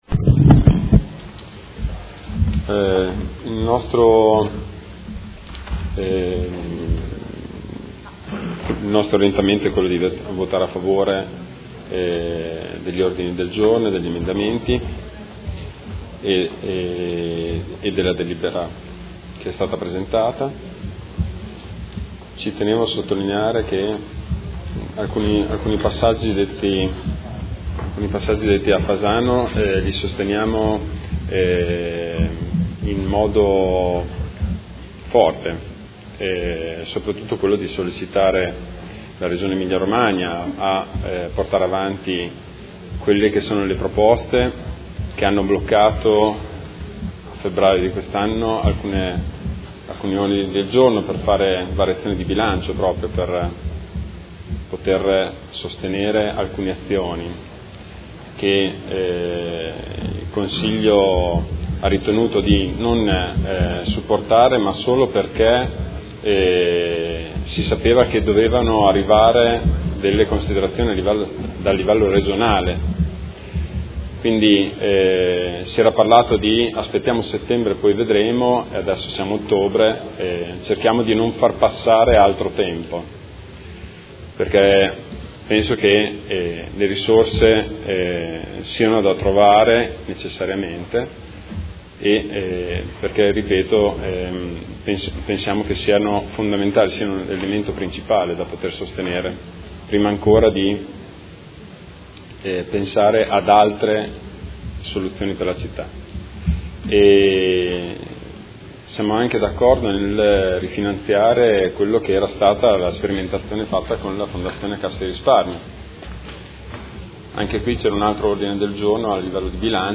Marco Bortolotti — Sito Audio Consiglio Comunale
Dichiarazione di voto su proposta di deliberazione e ordini del giorno sul tema della solidarietà civica